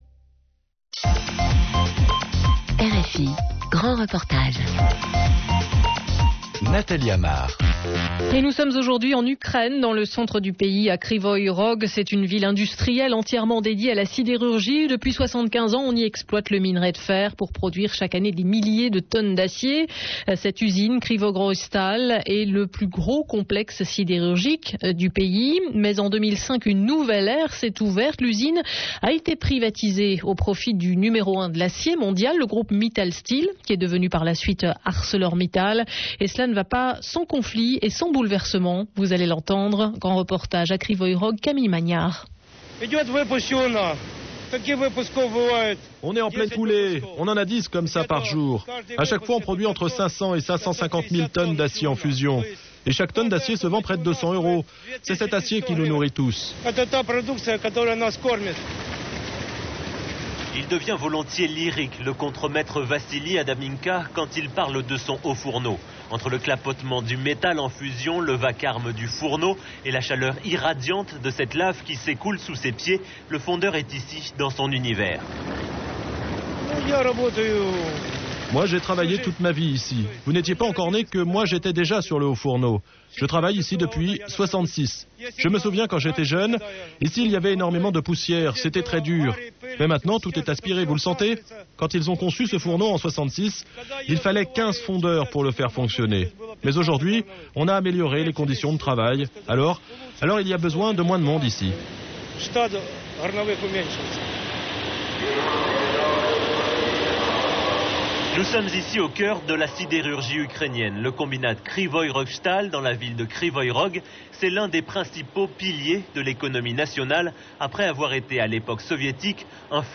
Un grand reportage